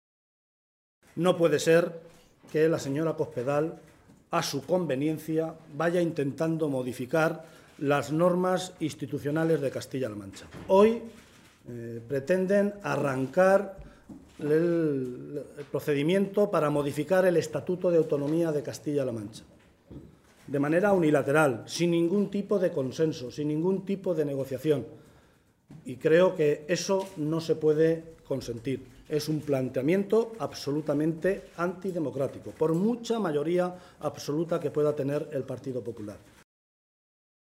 José Luís Martínez Guijarro, portavoz del Grupo Parlamentario Socialista
Cortes de audio de la rueda de prensa